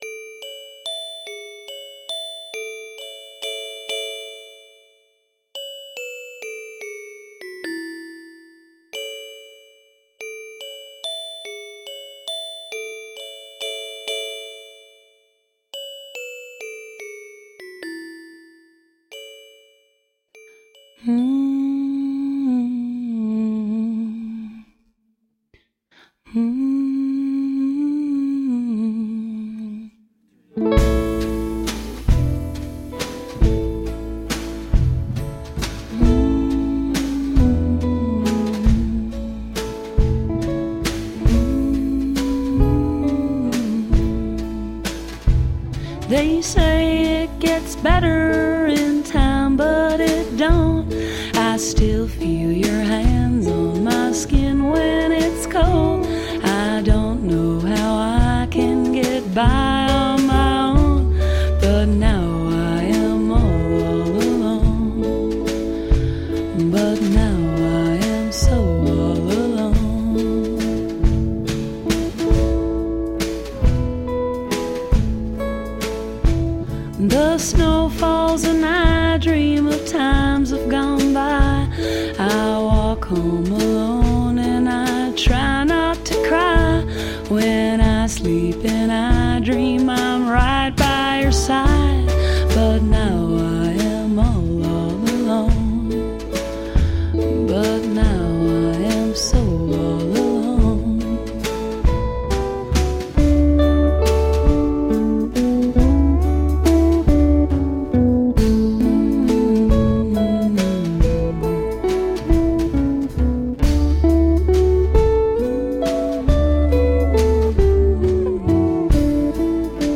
Country, rockabilly, western swing and lounge.
Tagged as: Alt Rock, Folk-Rock, Country